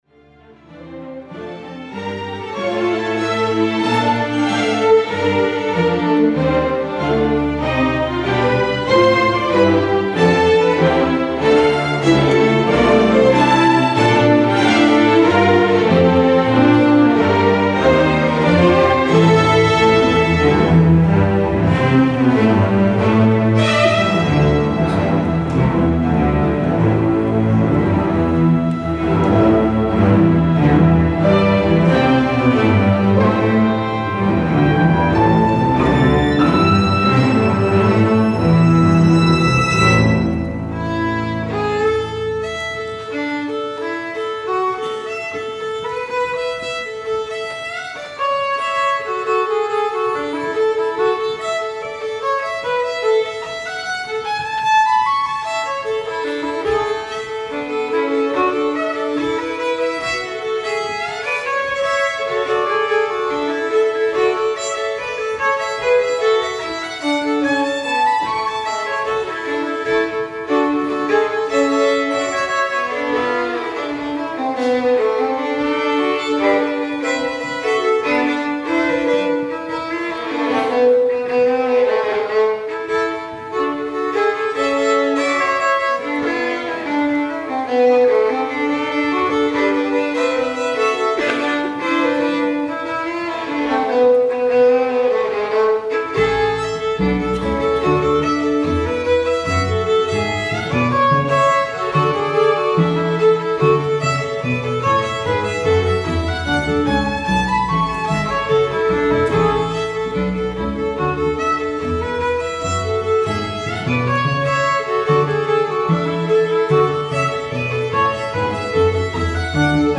From the St Magnus Festival, Orkney.